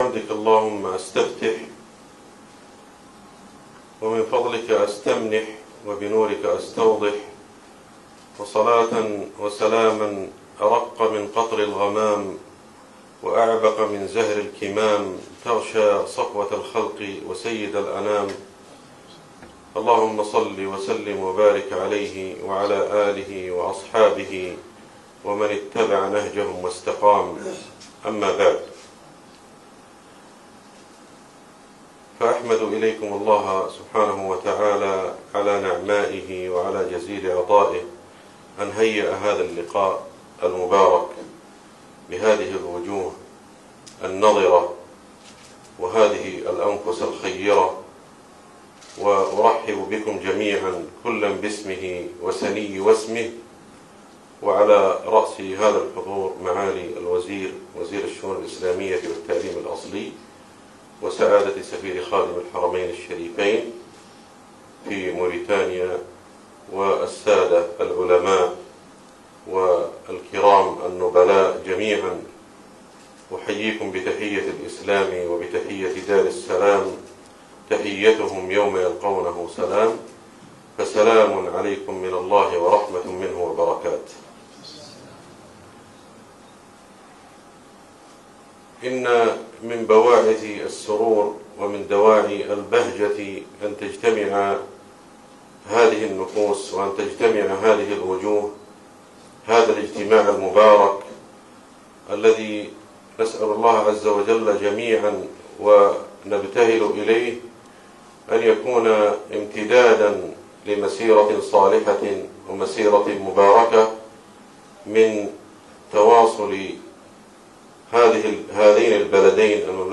كلمة الشيخ أحمد الحذيفي بمناسبة زيارته لوزارة الشؤون الإسلامية والتعليم الأصلي في موريتانيا > زيارة الشيخ أحمد الحذيفي لـدولة موريتانيا > تلاوات و جهود الشيخ أحمد الحذيفي > المزيد - تلاوات الحرمين